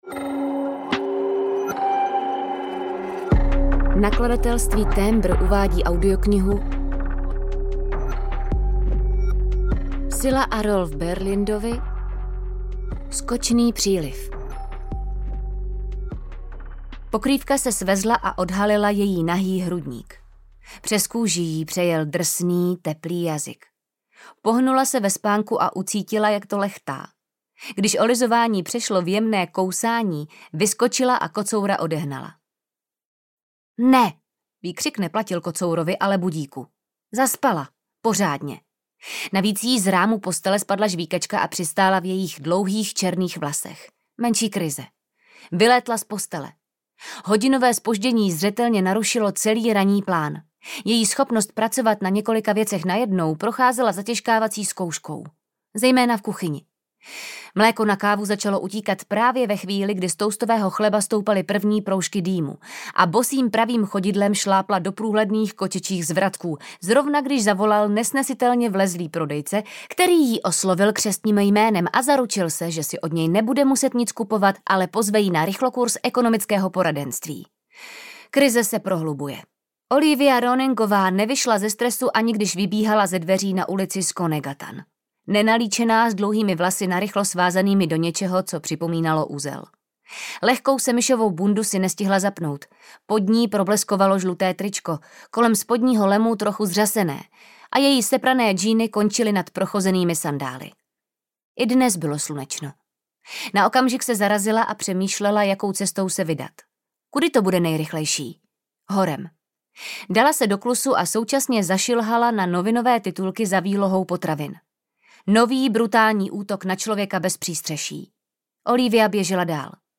Ukázka z knihy
Čte Anita Krausová a Martin Stránský
Natočeno ve studiu All Senses Production s. r. o.
skocny-priliv-audiokniha